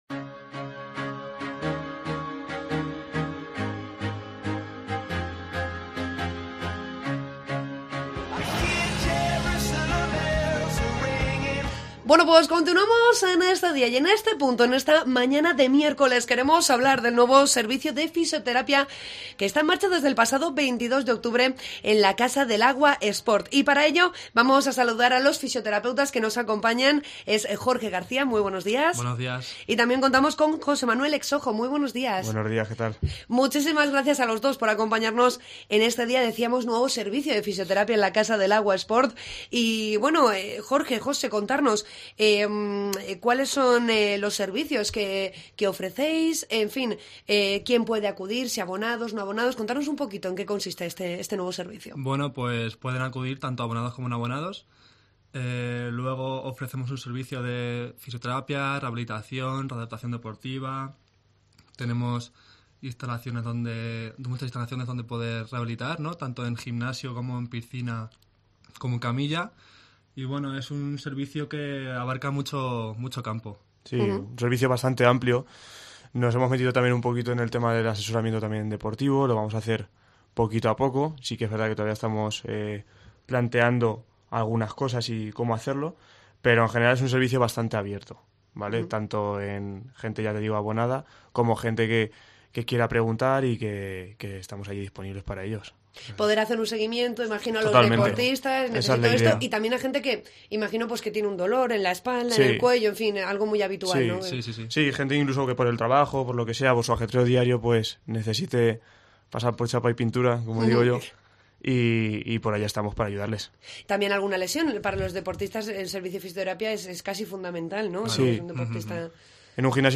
AUDIO: Entrevistamos a los fisioteapeutas